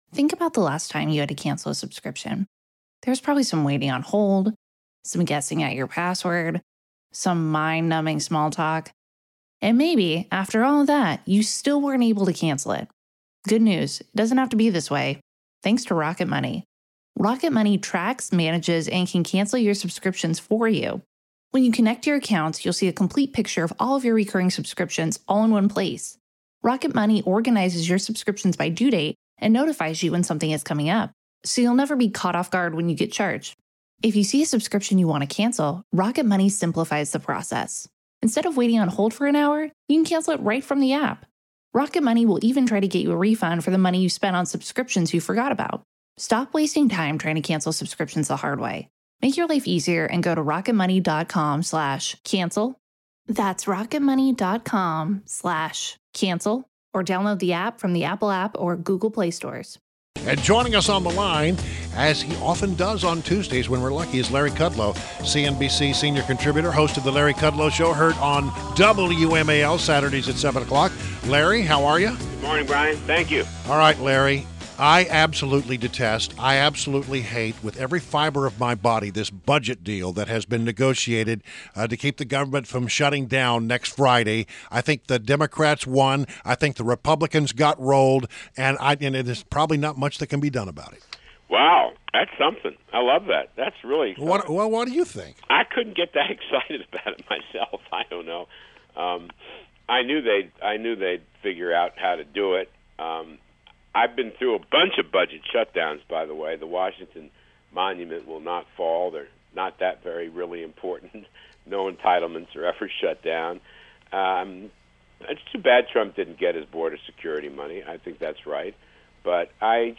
WMAL Interview - LARRY KUDLOW - 05.02.17